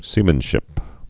(sēmən-shĭp)